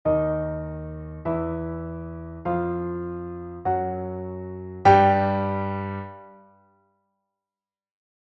der Ton Fisis, Notation
der-Ton-Fisis.mp3